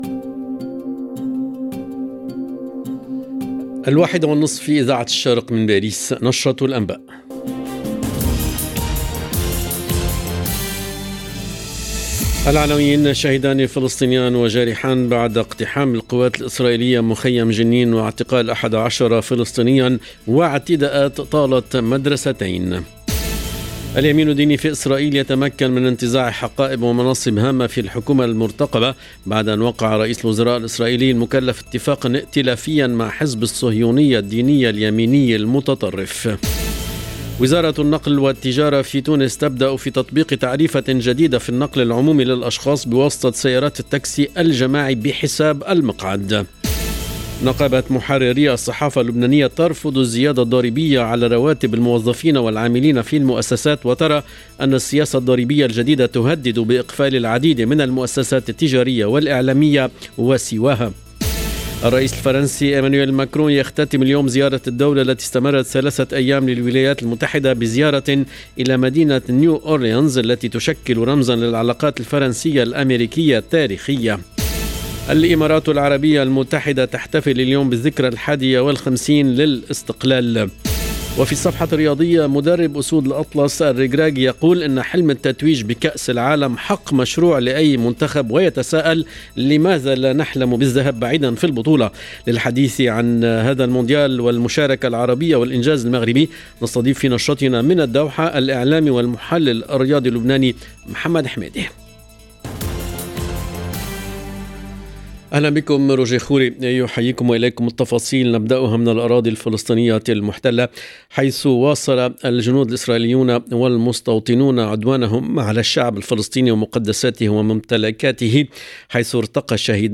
LE JOURNAL DE 13H30 EN LANGUE ARABE DU 2/12/22